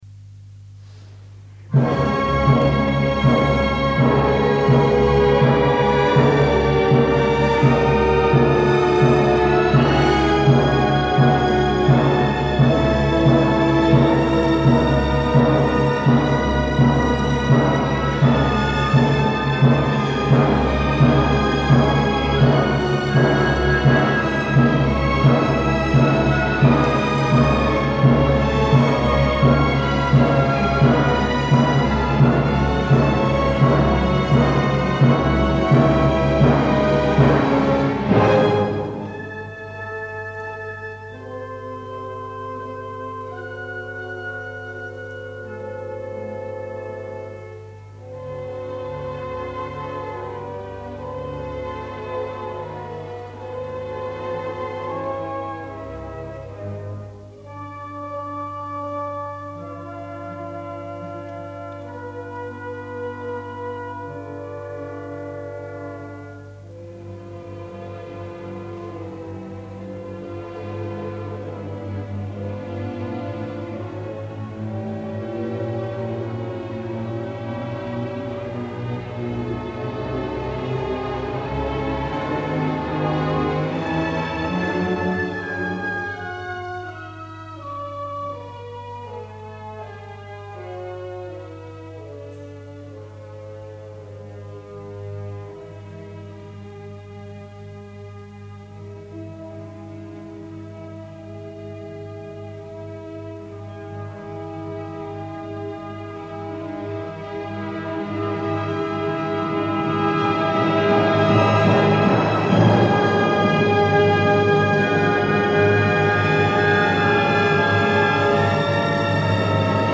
欧州演奏旅行